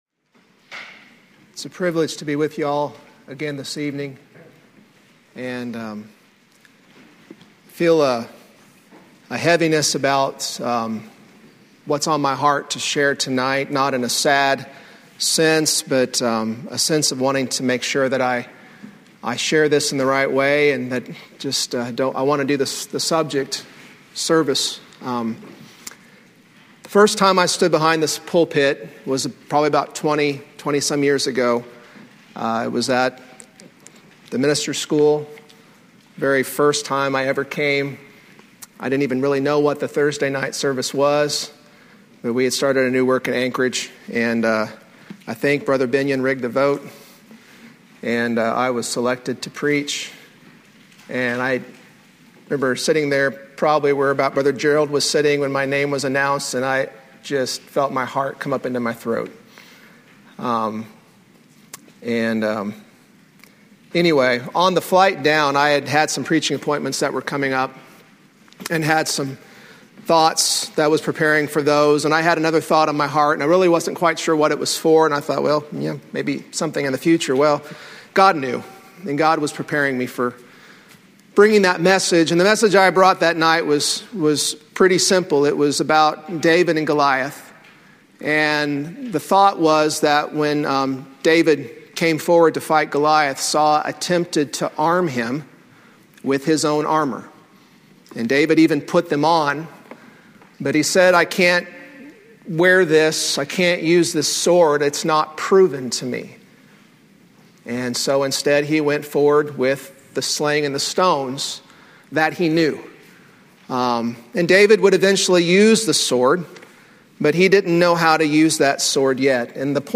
Thursday night sermon from the 2004 session of the Old Union Ministers School.